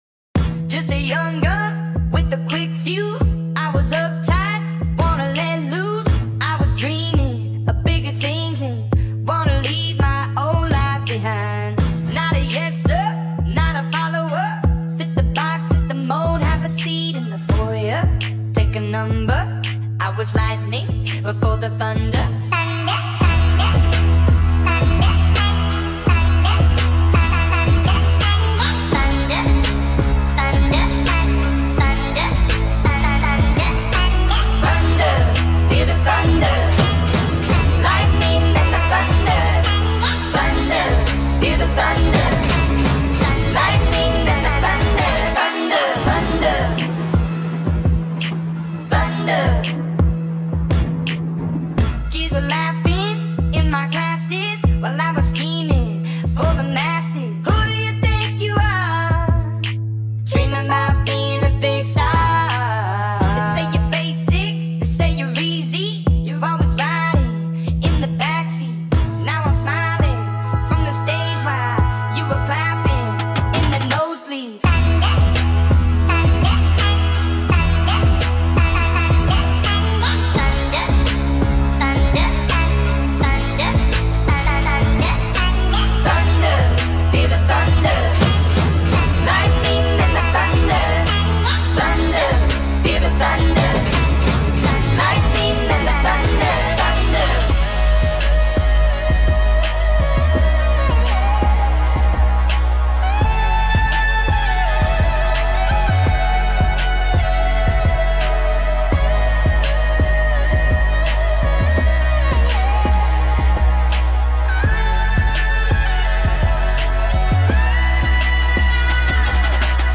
in our key of B (up 4 half steps)